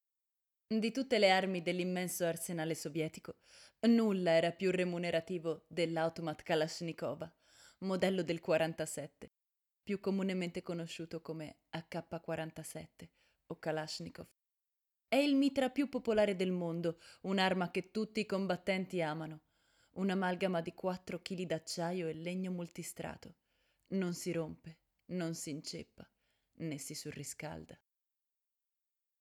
voce giovane, brillante, calda, versatile per spot, documentari, doppiaggi, audiolibri e quant'altro.
Sprechprobe: Sonstiges (Muttersprache):